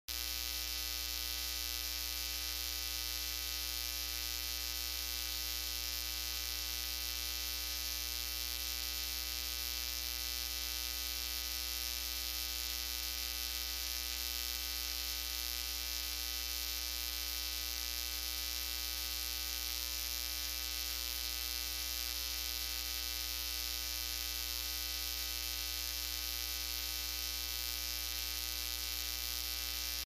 3. 누전 잡음(Hum Noise)
아마 포터블형 찬송가 반주기를 강단에 올려 놓고 트는 교회에서 가장 많이 발생하는 것이 누전 잡음일 것입니다. 주파수 측정을 하면 국내 전기 규격인 60Hz의 잡음으로 정확하게 나타납니다.(발전기 제네레이터가 1초에 60회 회전하며 만들어서 60Hz가 나옵니다.)
60hz-audio-buzz-example.mp3